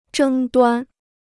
争端 (zhēng duān) Free Chinese Dictionary